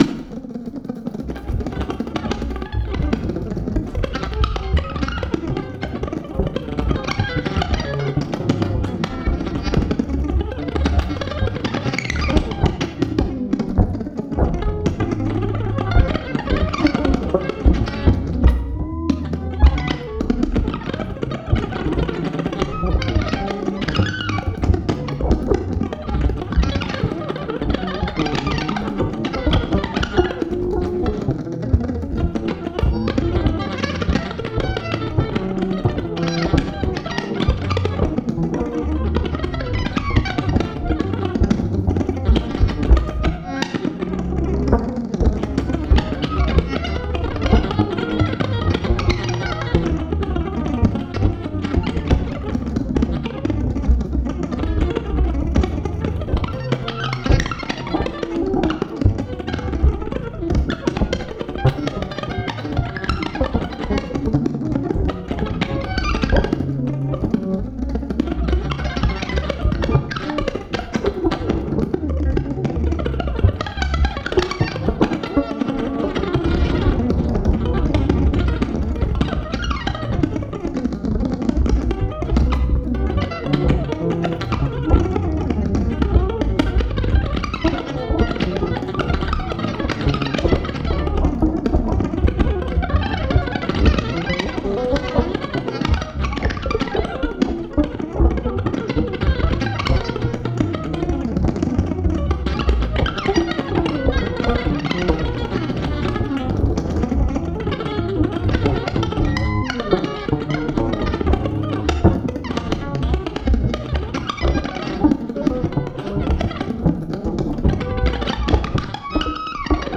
共演者はおりませんし、後からのオーバーダビングもされていません。
たいへん溌剌として元気良く、グルーヴの良い演奏が記録されており